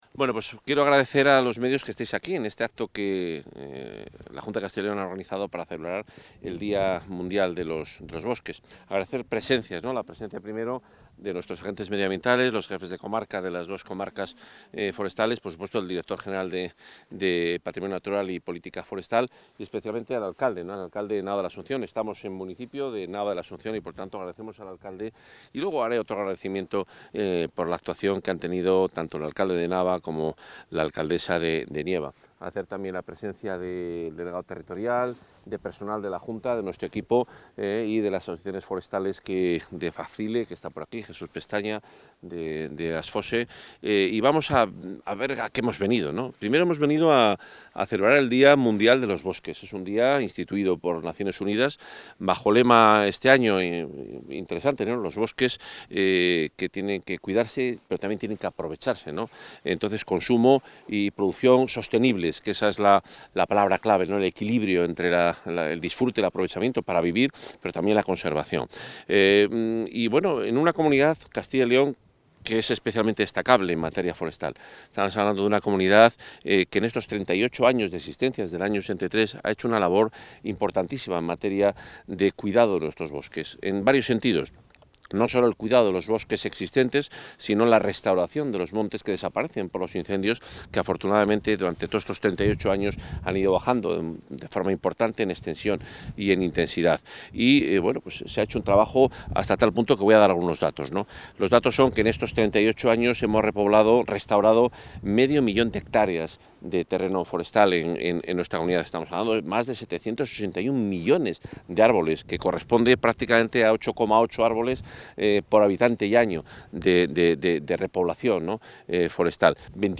Intervención del consejero de Fomento y Medio Ambiente en funciones.